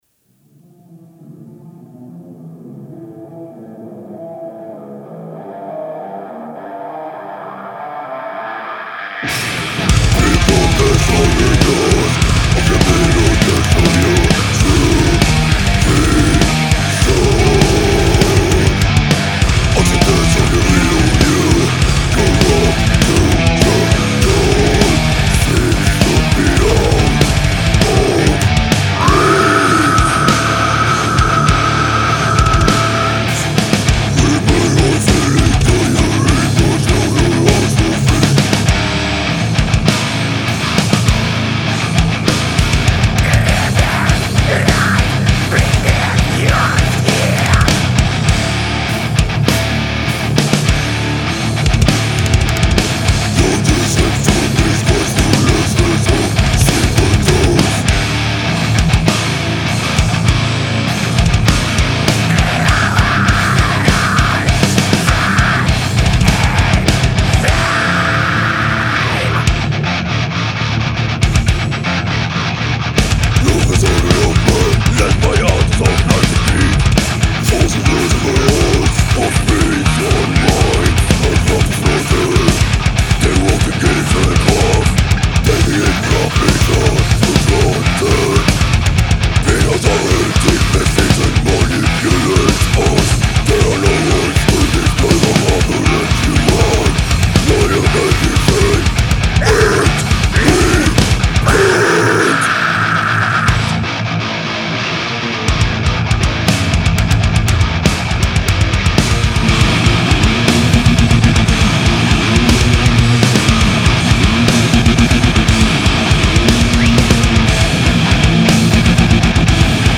Gatunek: Deathcore/Djent/groove